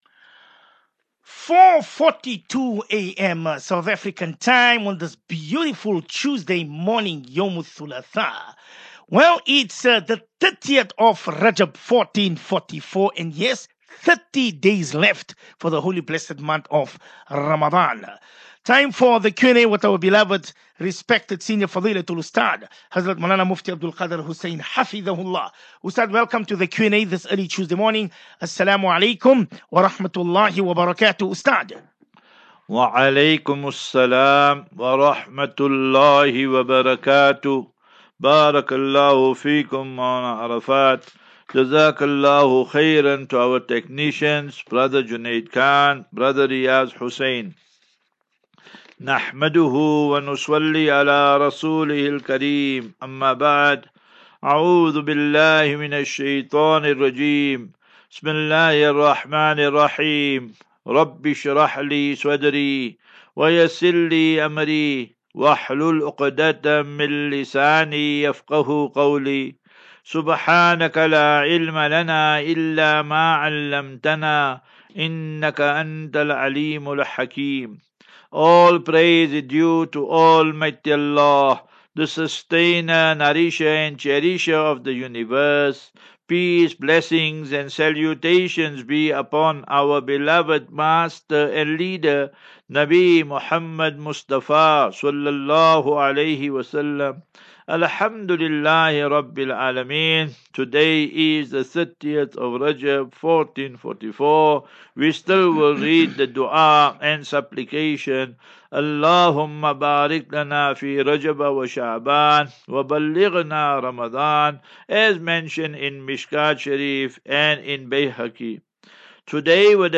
View Promo Continue Install As Safinatu Ilal Jannah Naseeha and Q and A 21 Feb 21 Feb 23- Assafinatu-Illal Jannah 36 MIN Download